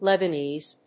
音節Leb・a・nese 発音記号・読み方
/lèbəníːz(米国英語), lɛ.bəˈniːz(英国英語)/